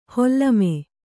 ♪ hollame